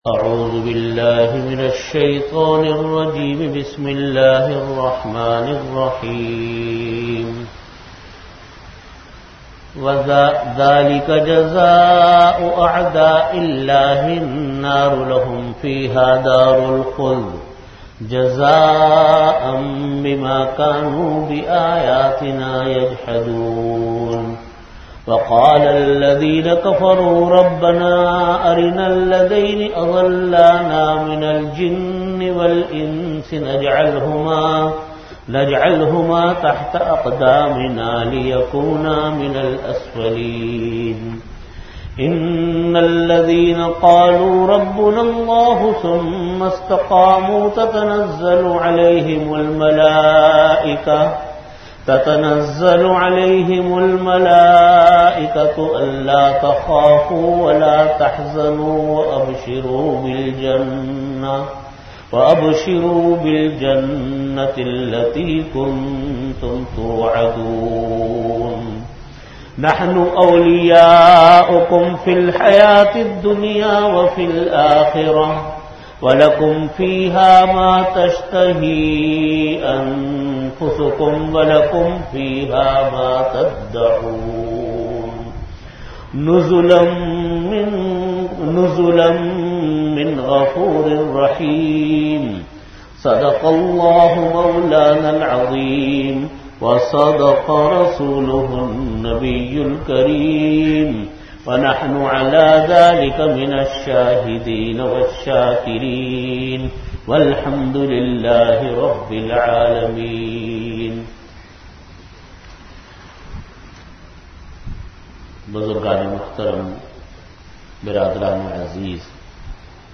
Category: Tafseer
Venue: Jamia Masjid Bait-ul-Mukkaram, Karachi